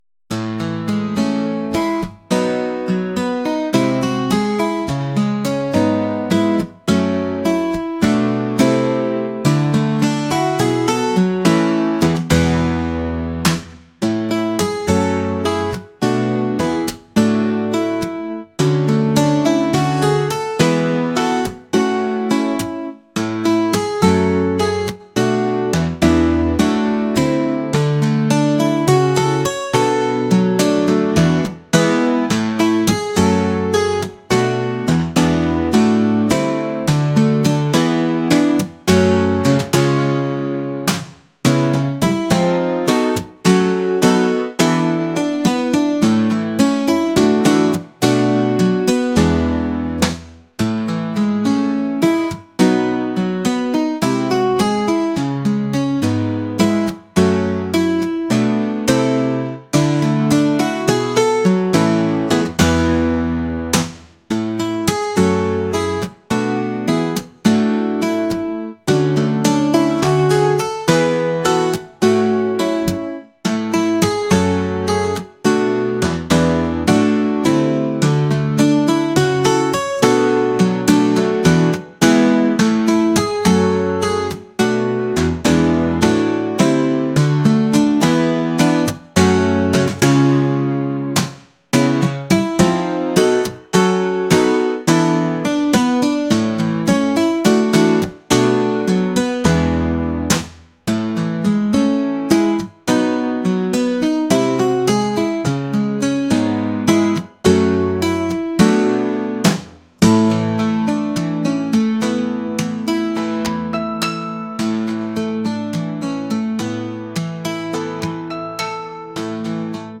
acoustic | latin | laid-back